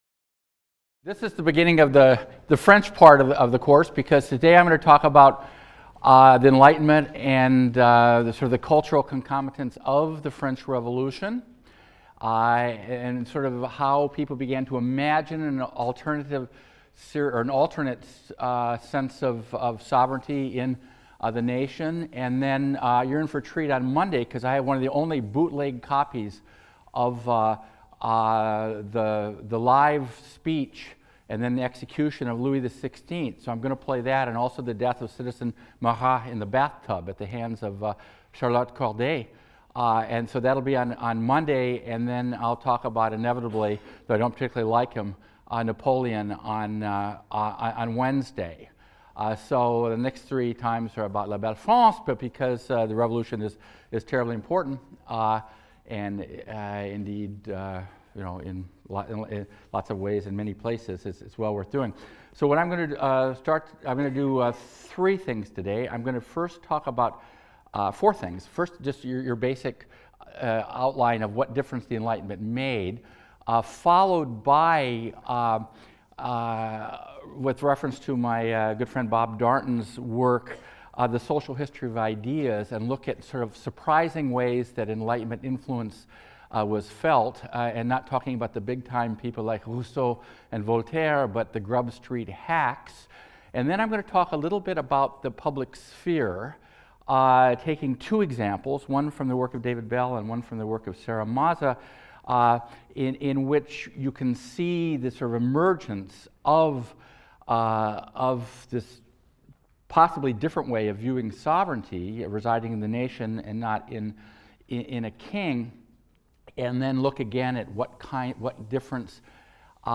HIST 202 - Lecture 5 - The Enlightenment and the Public Sphere | Open Yale Courses